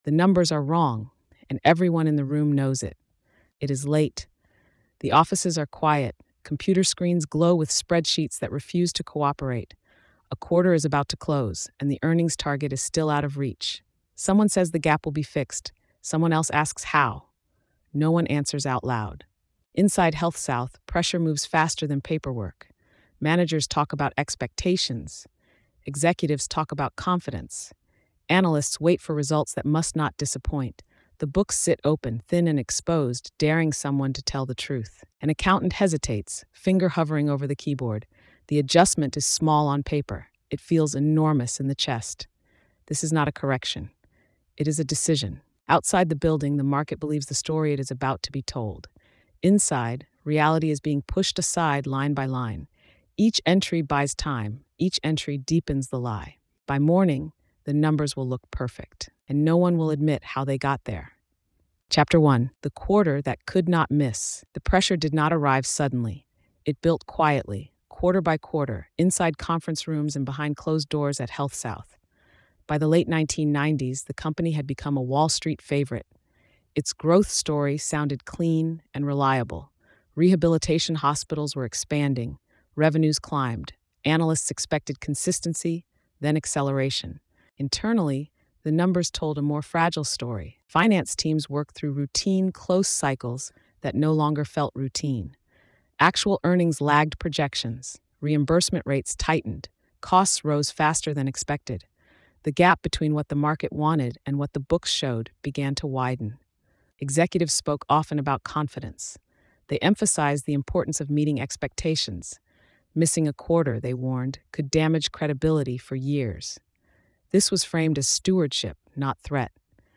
Faking the Books: HealthSouth’s Earnings Mirage is a gritty investigative narrative that examines how relentless Wall Street pressure, executive expectations, and a culture of silence allowed a massive financial deception to grow inside HealthSouth. Told in a clear, journalistic voice, the series traces how routine accounting pressure hardened into systemic fraud, placing employees in impossible positions and distorting reality for investors, regulators, and the public. The story focuses on power, fear, and the cost of manufactured success, showing how corporate fraud is built not only on false numbers, but on human decisions made under sustained pressure.